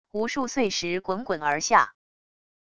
无数碎石滚滚而下wav下载